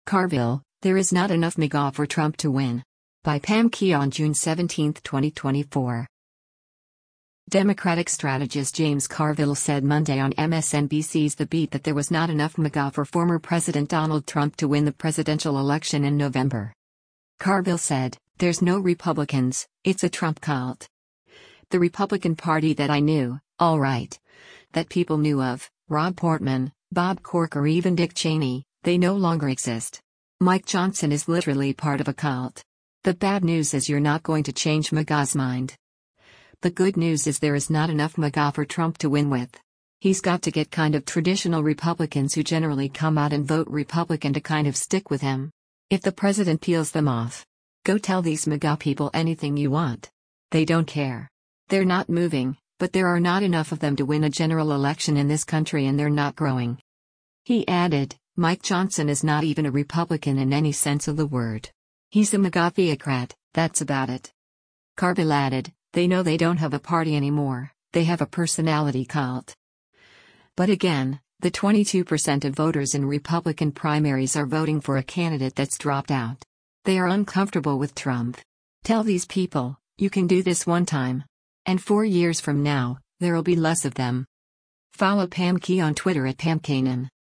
Democratic strategist James Carville said Monday on MSNBC’s “The Beat” that there was “not enough MAGA” for former President Donald Trump to win the presidential election in November.